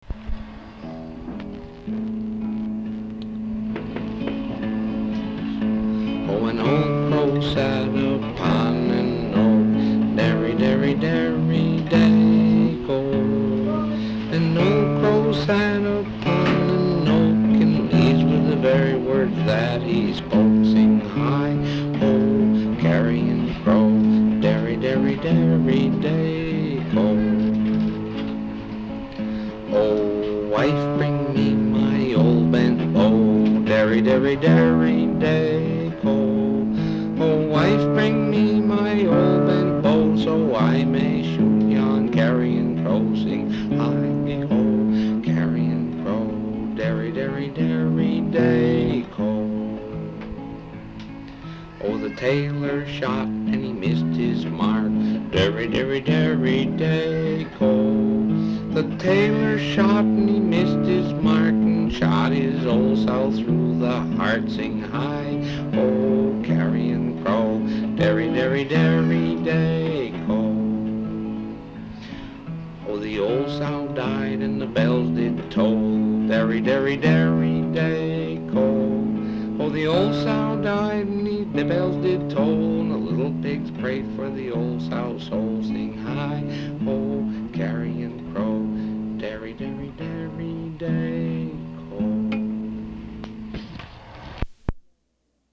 Folk songs, English--Vermont
sound tape reel (analog)